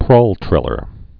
(prältrĭlər)